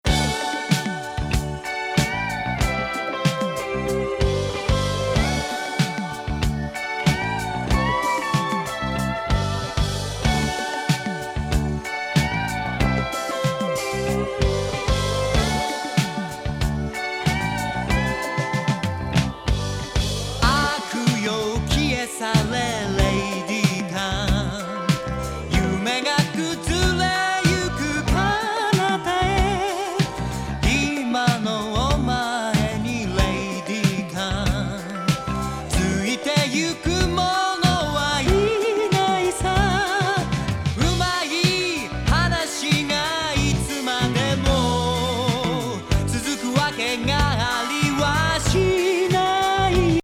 ヘビー・プログレッシブ・ロック